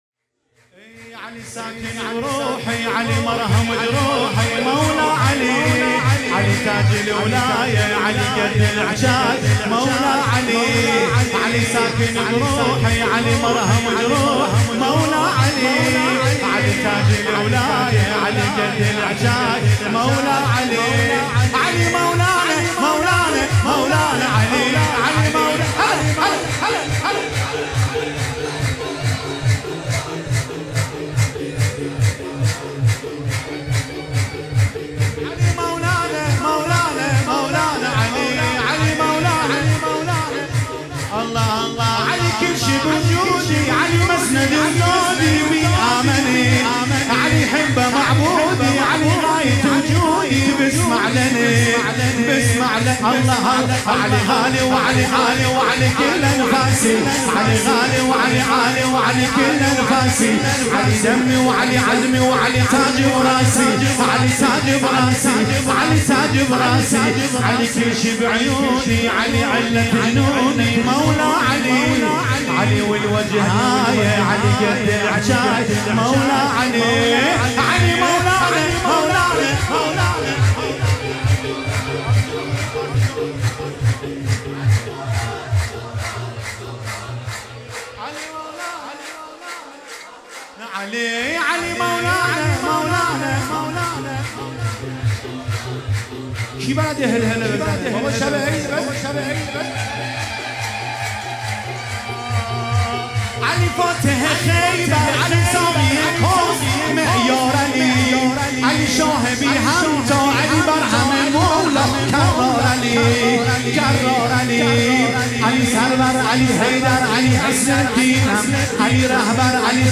سرود عربی
عید سعید غدیر خم جلسه خانگی